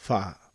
Wha - short vowel sound | 491_14,400